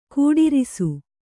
♪ kūḍirisu